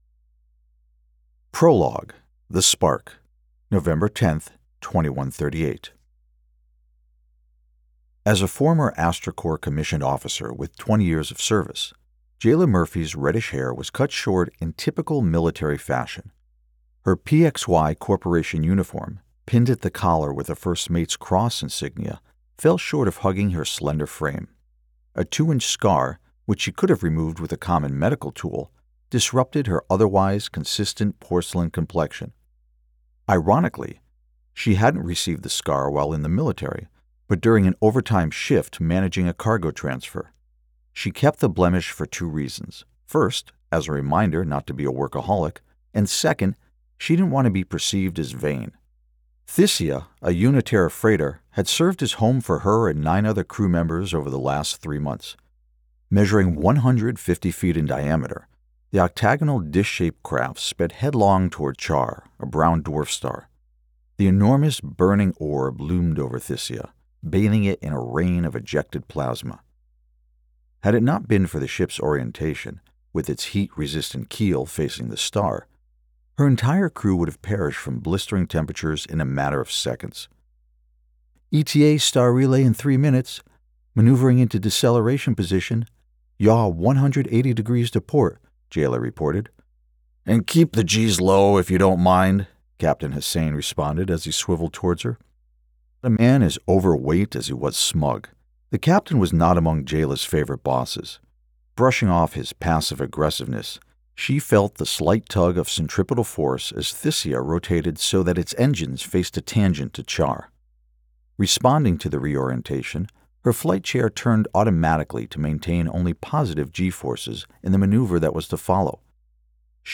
Audiobook - Fiction - SciFi
Middle Aged
My voice has been described as warm, trustworthy, and confident.